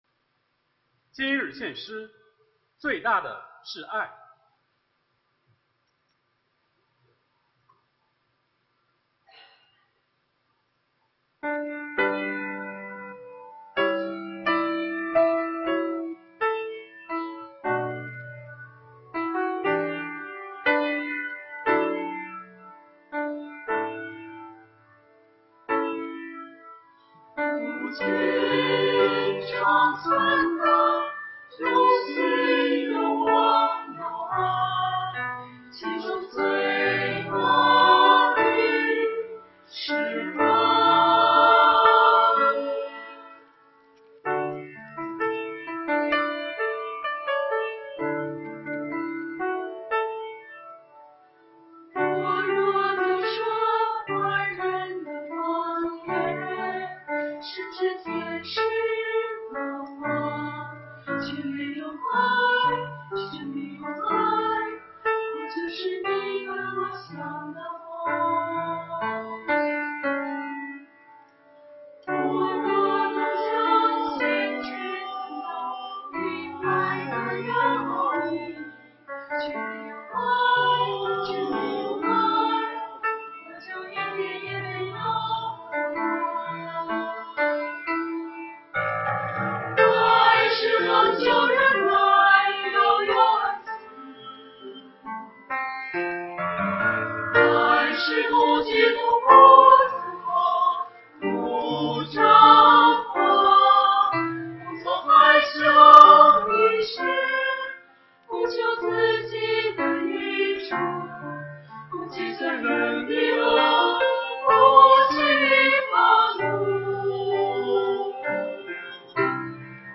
团契名称: 青年诗班 新闻分类: 诗班献诗 音频: 下载证道音频 (如果无法下载请右键点击链接选择"另存为") 视频: 下载此视频 (如果无法下载请右键点击链接选择"另存为")